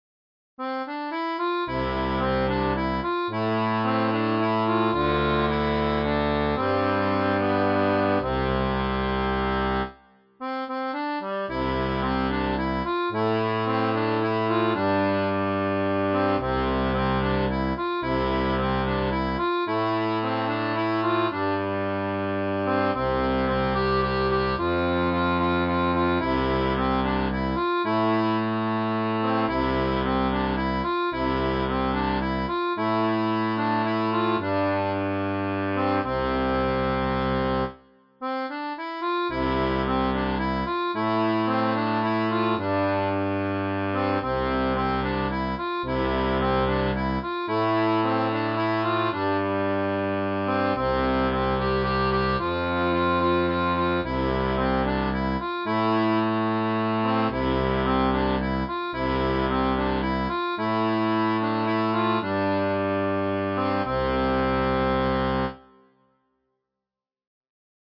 • une version pour accordéon diatonique à 2 rangs
Chanson française